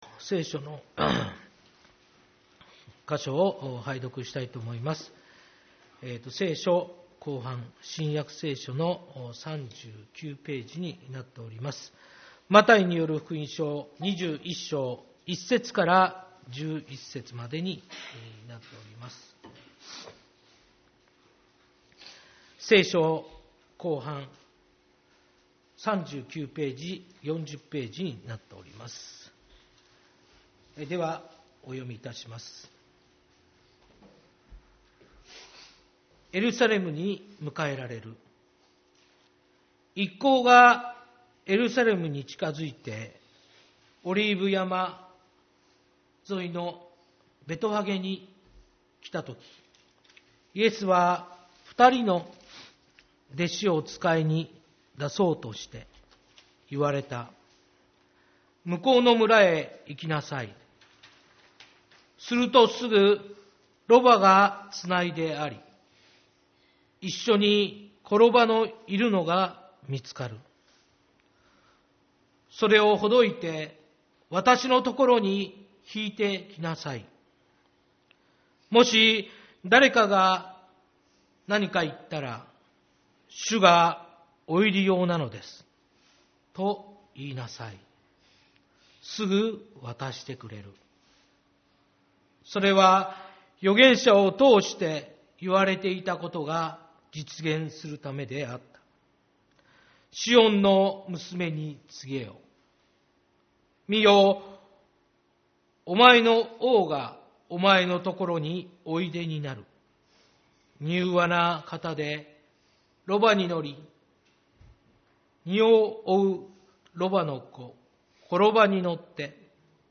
主日礼拝 「柔和な王」 マタイによる福音書21.1-11